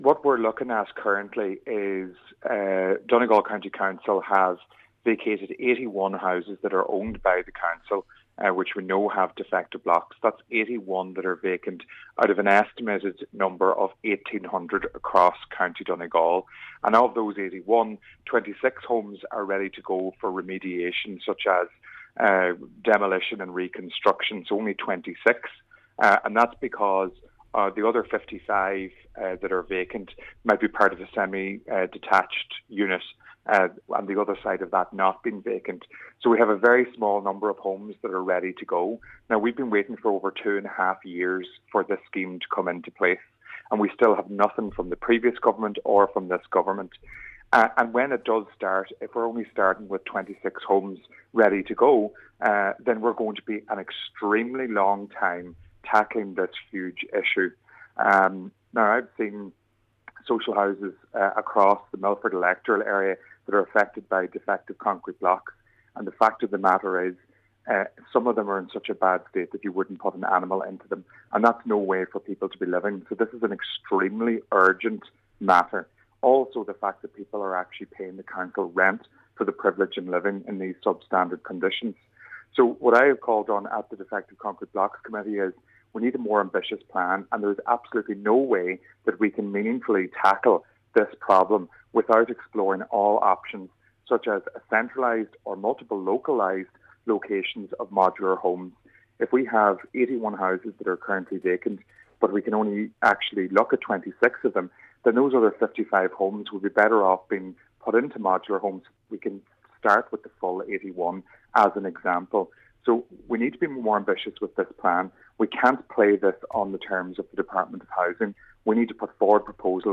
Cllr Declan Meehan says that figure is not enough…………….